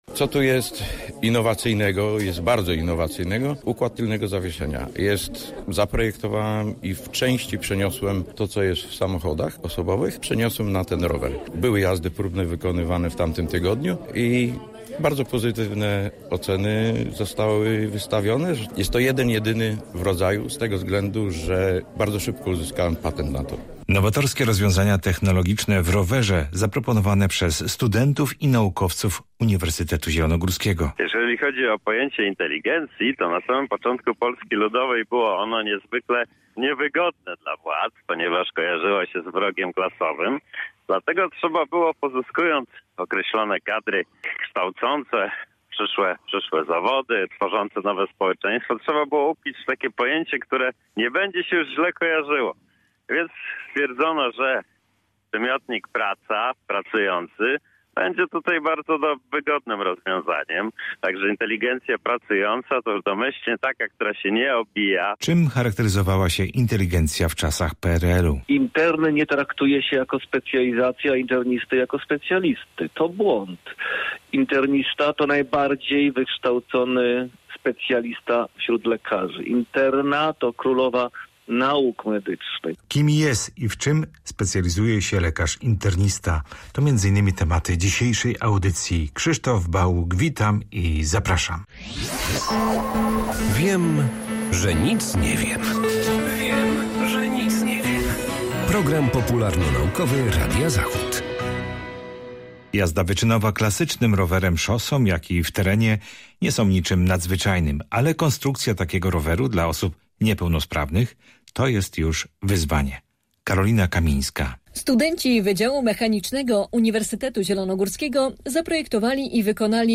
Program popularnonaukowy